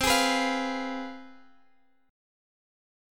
CM7sus4#5 chord